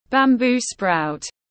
Măng tiếng anh gọi là bamboo sprout, phiên âm tiếng anh đọc là /bæmˈbuː spraʊt/
Bamboo sprout /bæmˈbuː spraʊt/